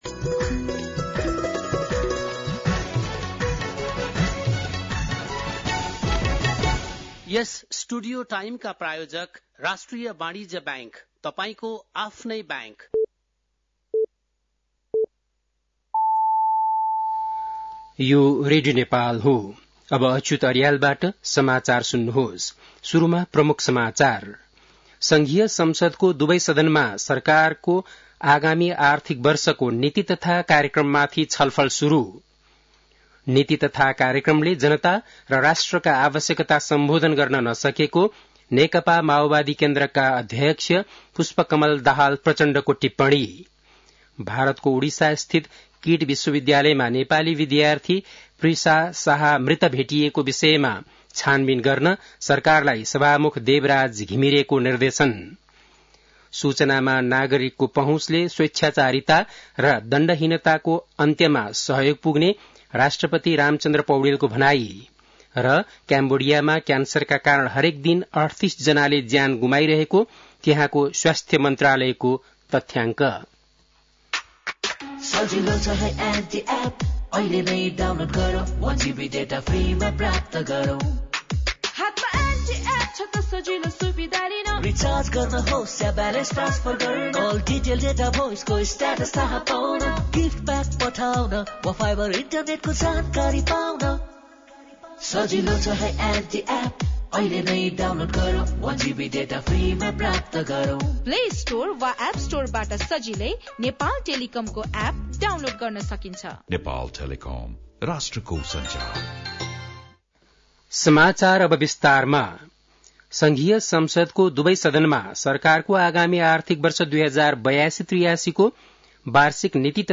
बेलुकी ७ बजेको नेपाली समाचार : २२ वैशाख , २०८२
7.-pm-nepali-news-2.mp3